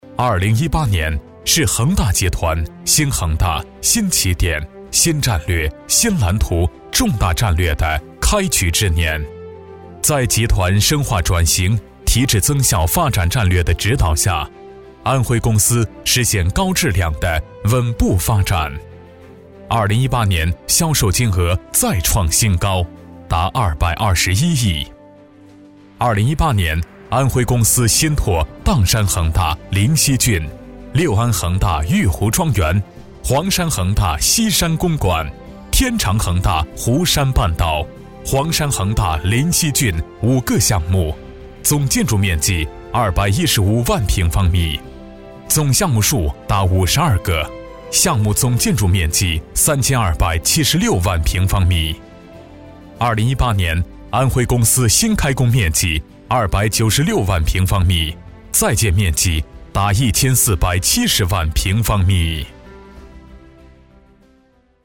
轻松自然 品牌宣传片
大气厚重，带点时尚感。